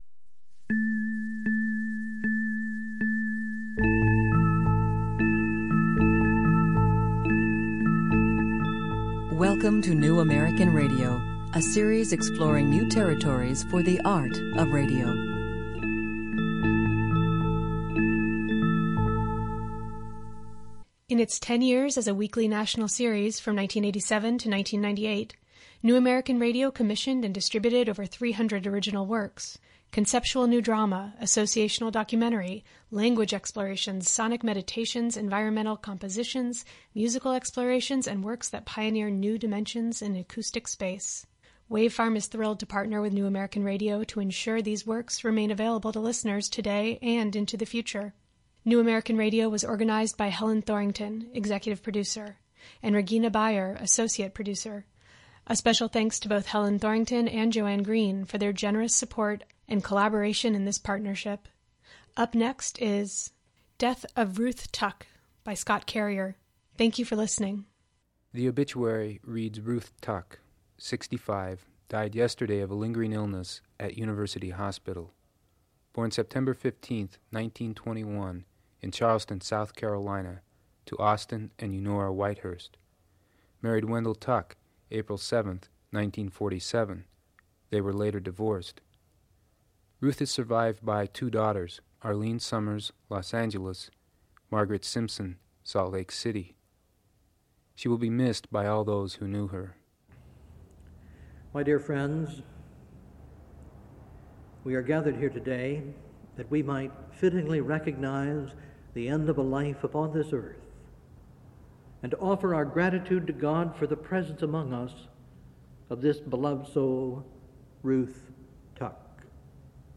A fictionalized ethnography which explores how we handle death. Composed of a series of dramatically produced interviews which people involved in a specific death: the pathologist, the divorced husband, an old friend, an estranged daughter, and so on, right up to the gravedigger.